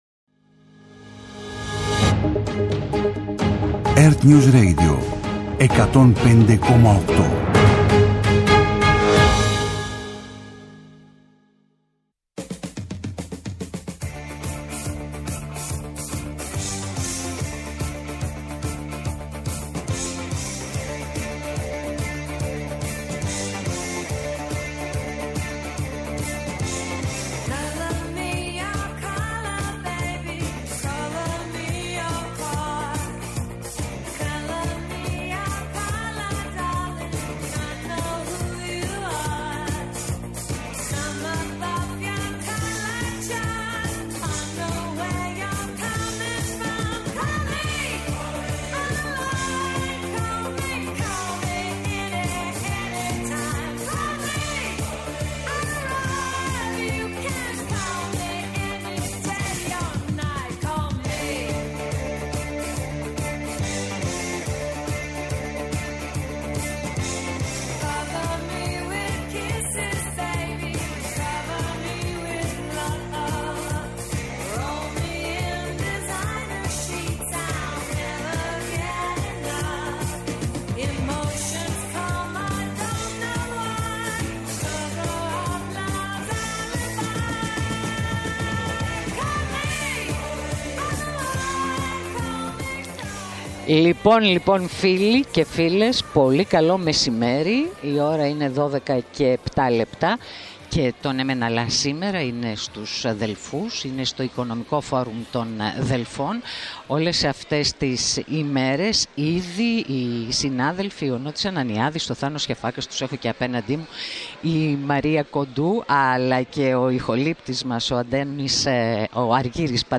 Σήμερα από το studio του ΕΡΤnews Radio 105,8 στο Οικονομικό Φόρουμ των Δελφών, καλεσμένοι της εκπομπής: